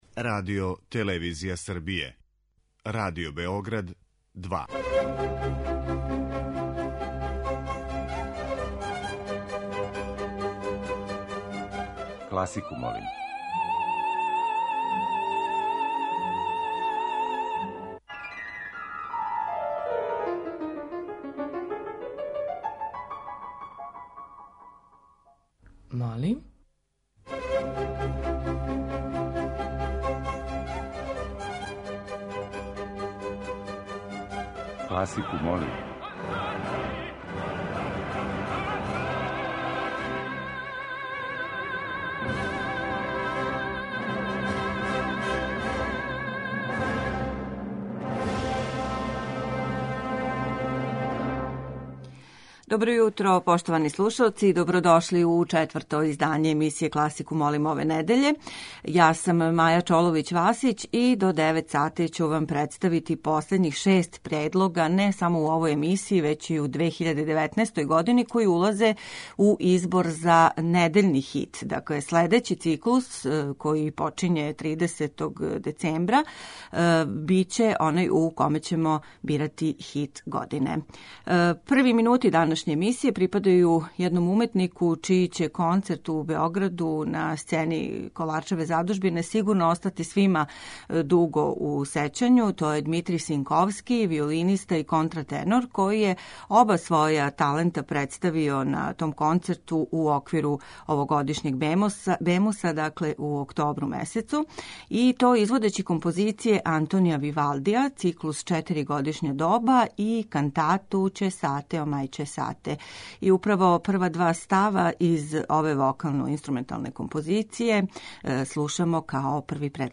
Недељна топ-листа класичне музике Радио Београда 2
Предпразнични циклус емисија Класику молим, уједно и последњи у коме бирамо хит недеље, обојиће музика инспирисана Божићем.